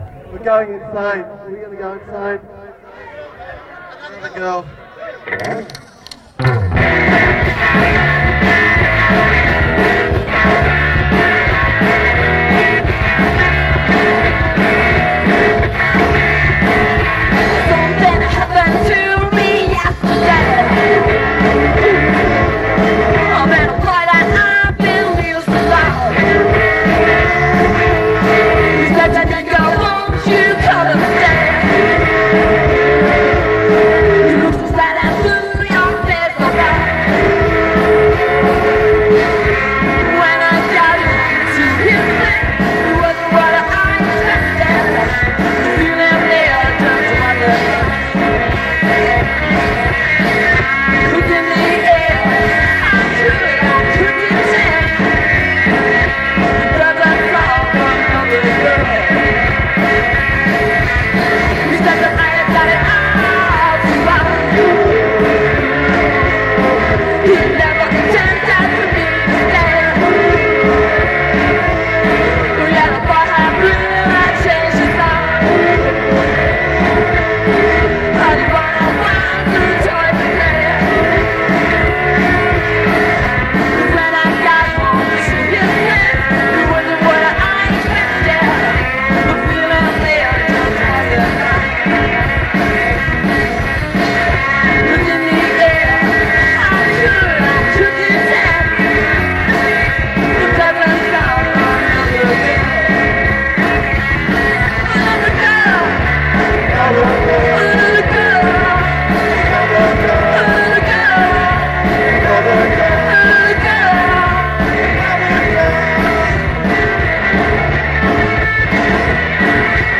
Recorded live at Sunray Sunday ’87.